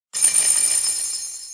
get_coins.mp3